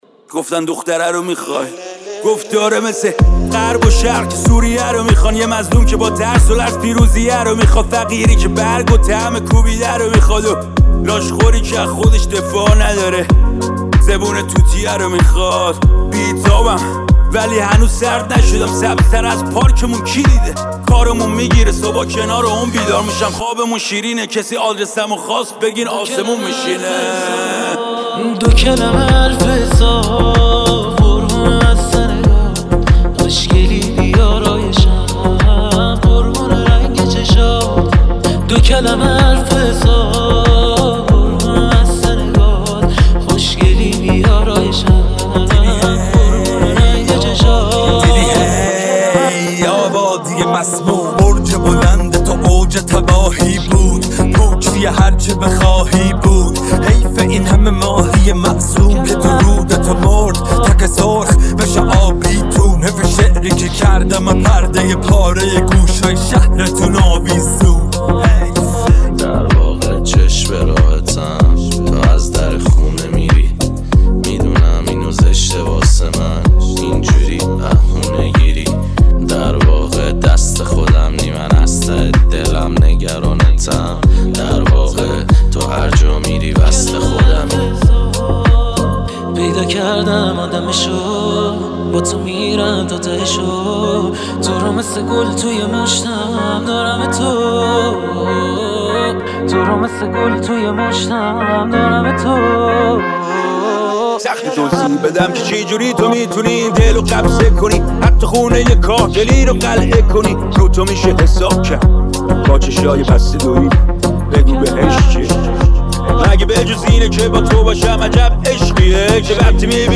دانلود ریمیکس جدید
ریمیکس رپ